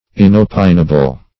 Search Result for " inopinable" : The Collaborative International Dictionary of English v.0.48: Inopinable \In`o*pin"a*ble\, a. [L. inopinabilis.